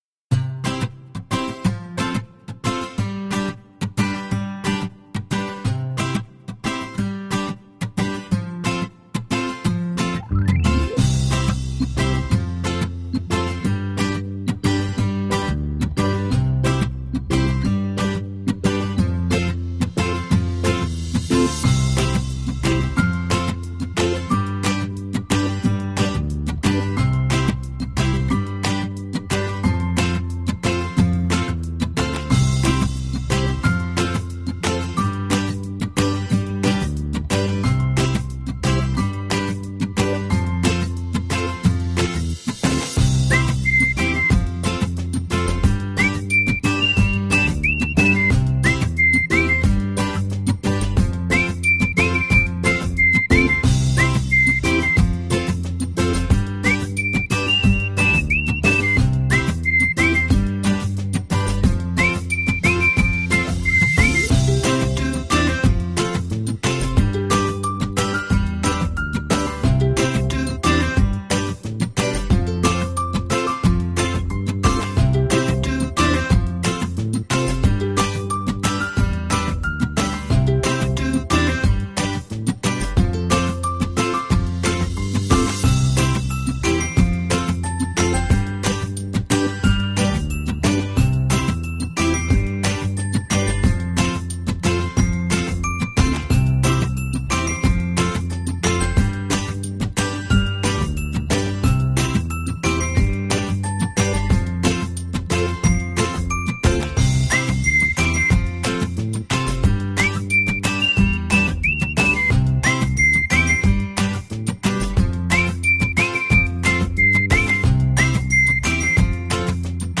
Вам понравится эта легкая фоновая беззаботная музыка